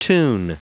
Prononciation du mot tune en anglais (fichier audio)
Prononciation du mot : tune